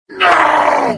moarg_hurt_02.mp3